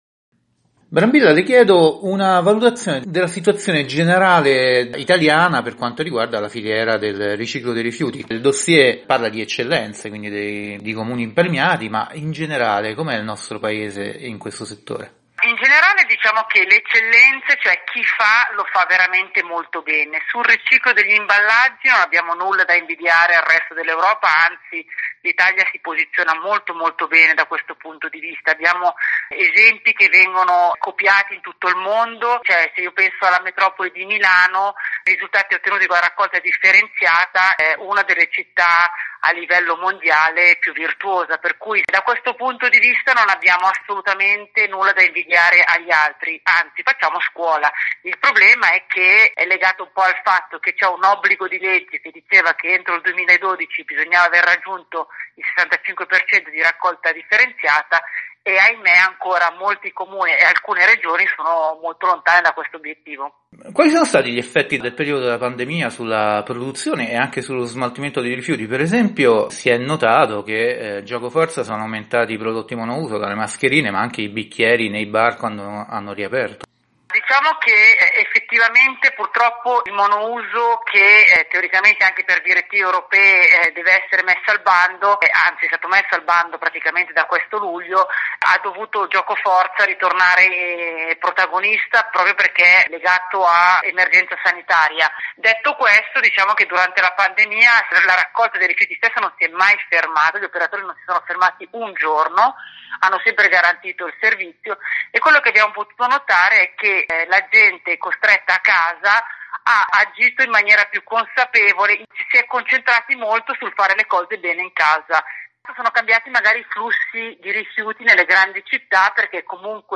Di seguito la versione integrale dell’intervista rilasciata a “Ecosistema”, la rubrica radiofonica di Earth Day Italia, trasmessa nel programma “Il Mondo alla Radio” di Radio Vaticana Italia.